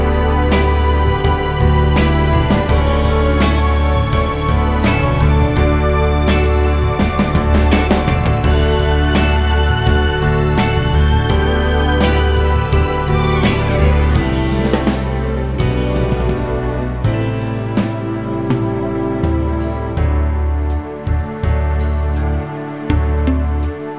For Solo Male Pop (1960s) 3:58 Buy £1.50